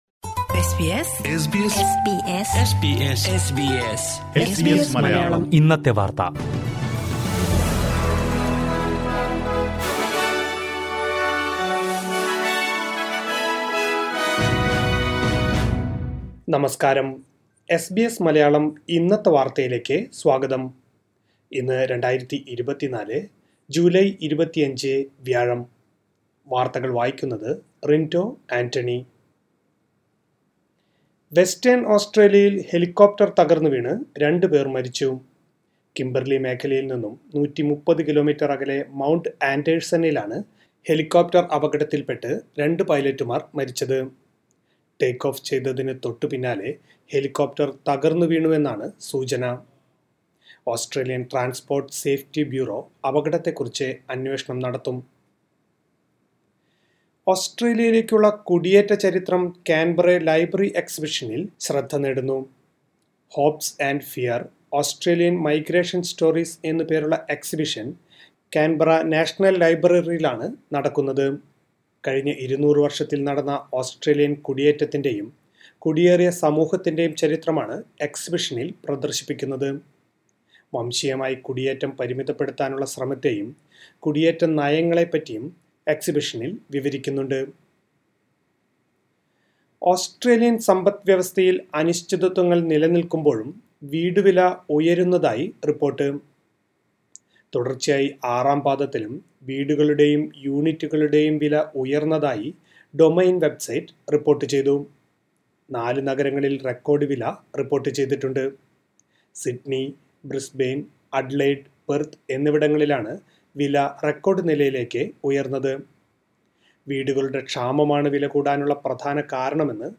2024 ജൂലൈ 25ലെ ഓസ്‌ട്രേലിയയിലെ ഏറ്റവും പ്രധാന വാര്‍ത്തകള്‍ കേള്‍ക്കാം...